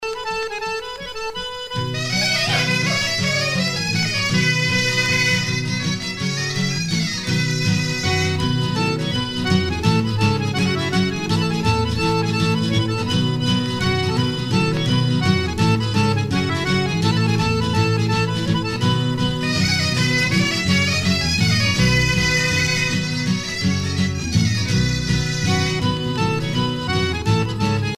danse : kost ar c'hoad
Pièce musicale éditée